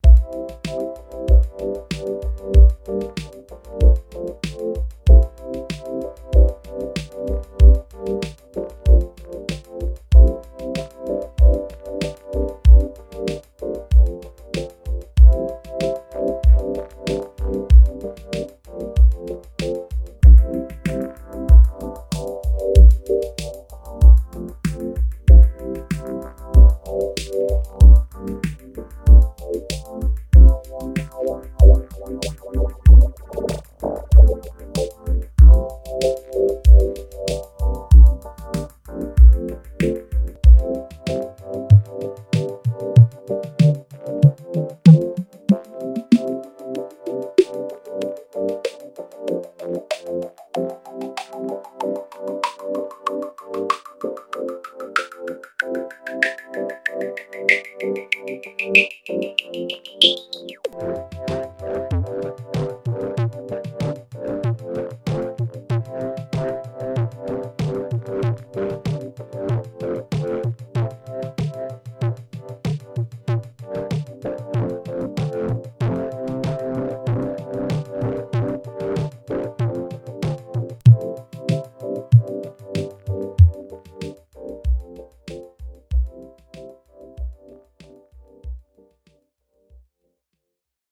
26th Jan 2007 | Experiment
AKAI MFC42 Filter
KORG SDD-1000 Delay
KORG DDD-5 Rhythm Machine / 12bit Pulse Code Modulation
Oberheim Matrix-1000 Synthesizer / Voltage-Controlled Oscillator